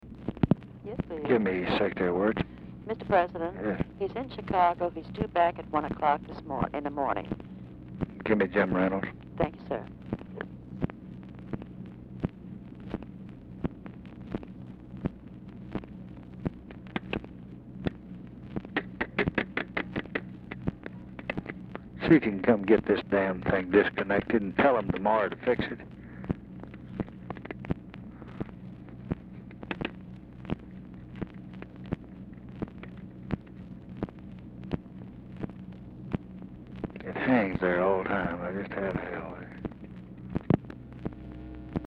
Telephone conversation # 13576, sound recording, LBJ and TELEPHONE OPERATOR, 10/22/1968, 10:23PM | Discover LBJ
Format Dictation belt
Location Of Speaker 1 Mansion, White House, Washington, DC
Specific Item Type Telephone conversation